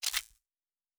pgs/Assets/Audio/Sci-Fi Sounds/Weapons/Weapon 16 Foley 1 (Laser).wav at master
Weapon 16 Foley 1 (Laser).wav